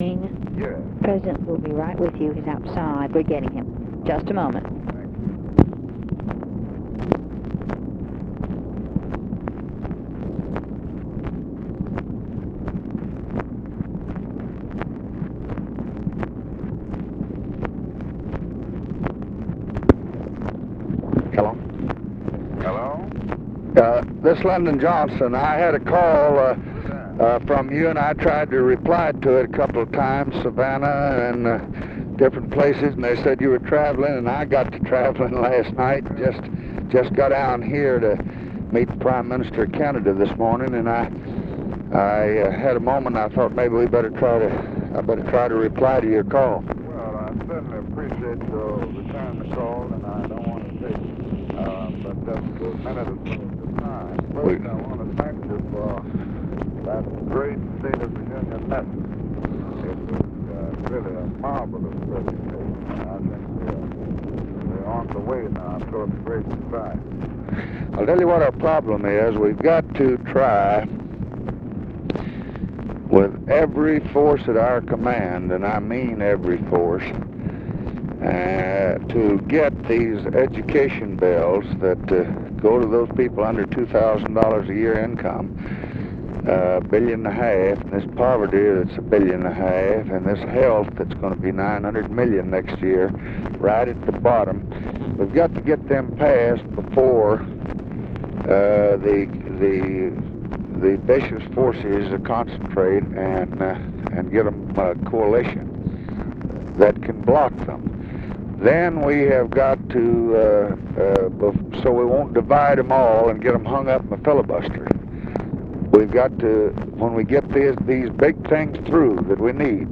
Conversation with MARTIN LUTHER KING and OFFICE SECRETARY, January 15, 1965
Secret White House Tapes